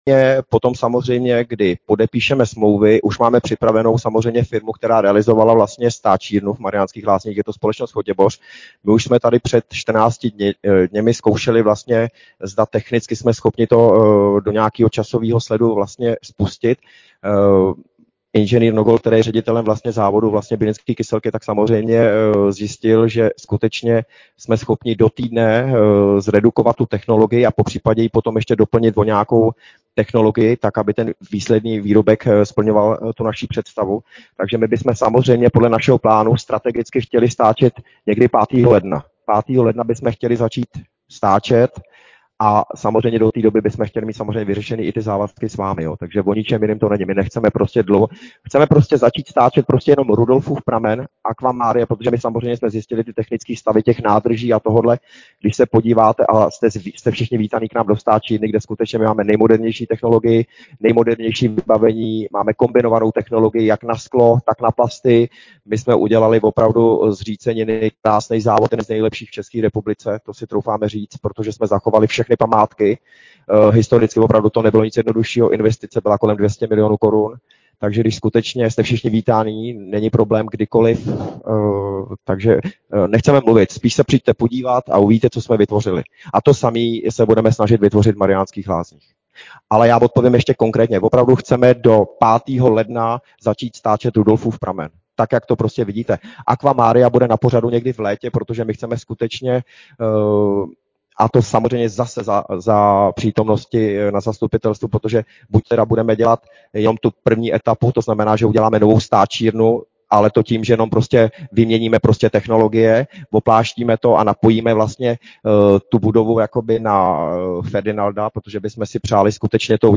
Město Mariánské Lázně: Navazující vysílání - v průběhu vysílání nastaly technické potíže s internetovým připojením, přenos již nebylo možné navázat na původní vysílání.